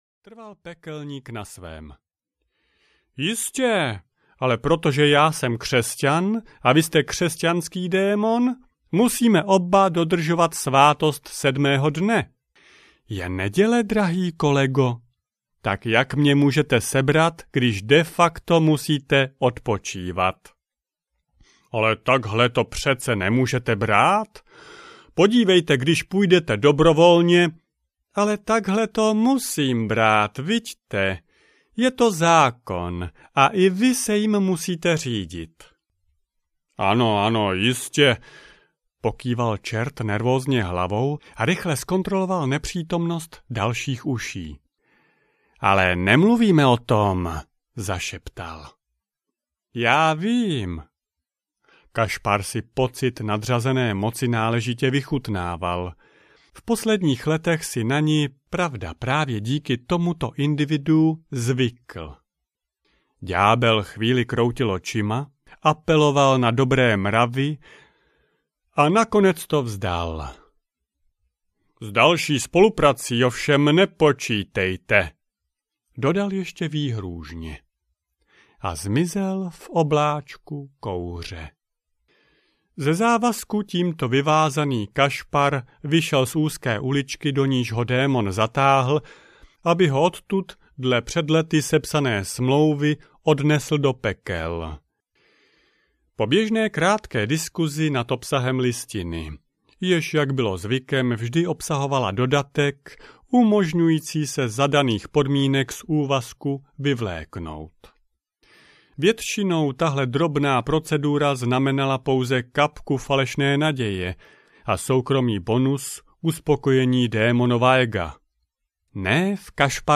Jak správně krmit démona audiokniha
Ukázka z knihy